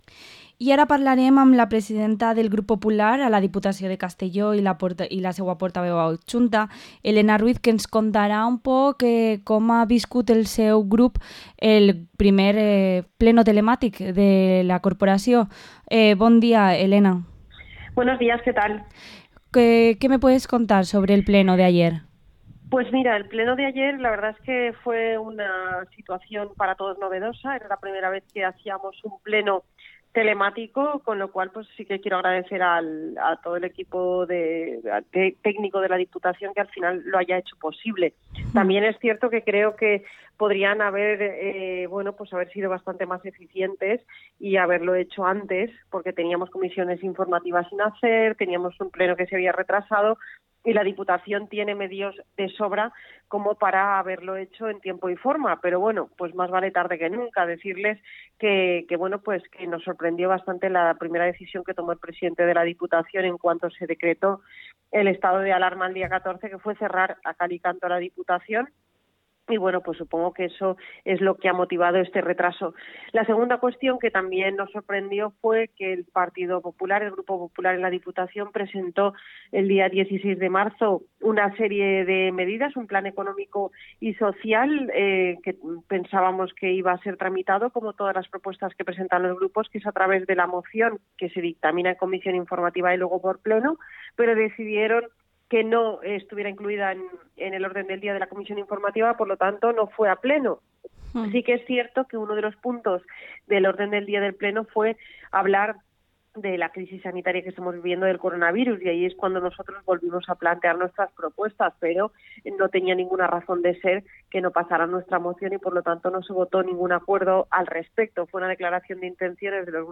Entrevista a la diputada provincial del PP, Elena Vicente Ruiz